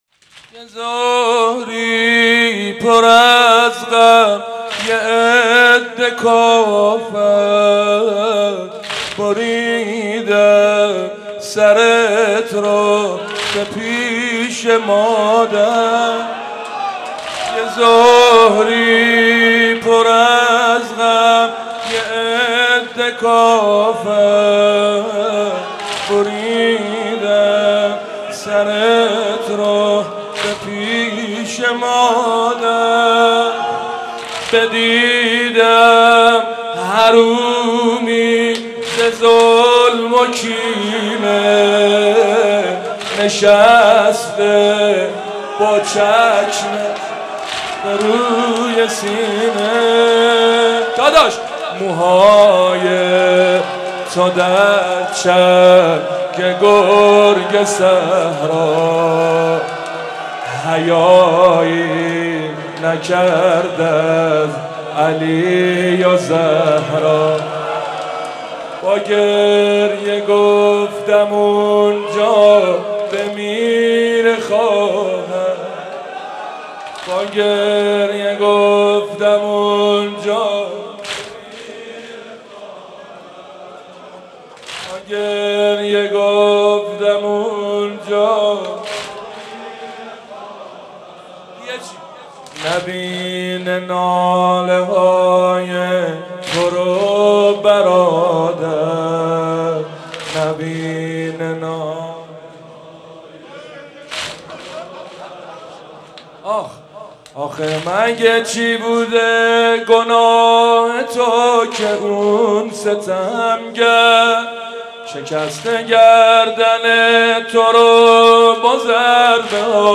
مناسبت : وفات حضرت زینب سلام‌الله‌علیها